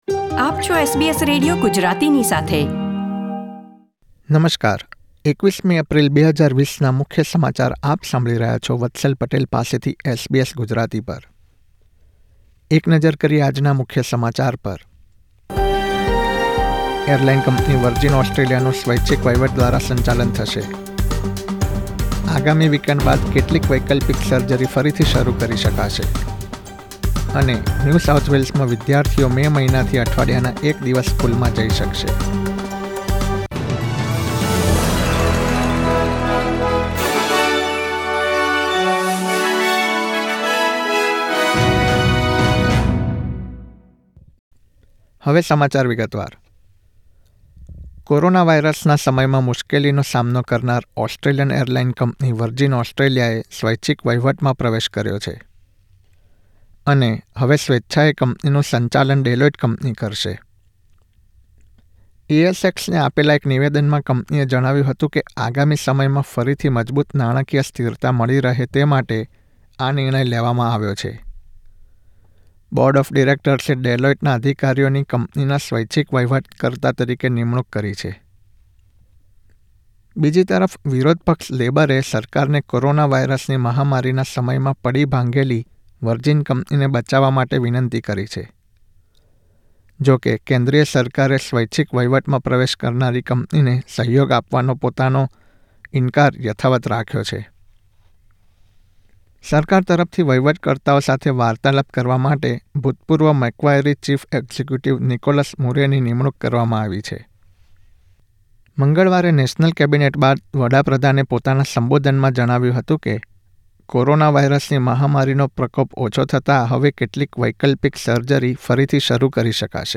૨૧ એપ્રિલ ૨૦૨૦ ના મુખ્ય સમાચાર